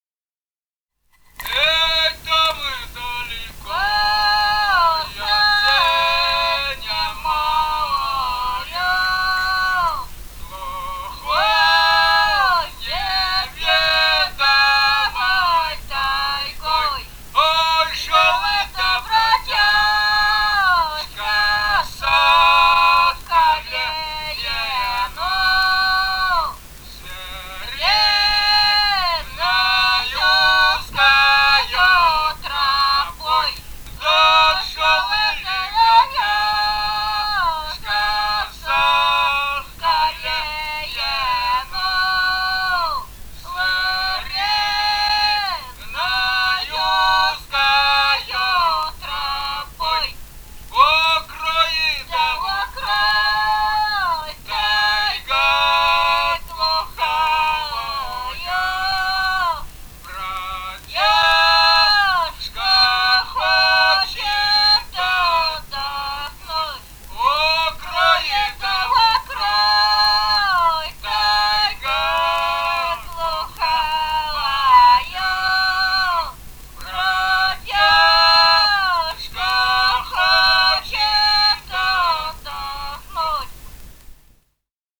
Бурятия, с. Желтура Джидинского района, 1966 г. И0904-04